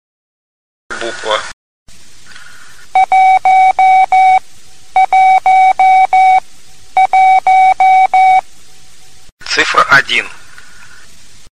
Предлагаю для обучения приема использовать еще большее приближение к нашим занятиям - сперва звучит слово "Буква", затем три раза повторяется морзе и в это время называем букву (или цифру), затем слушаем правильный ответ.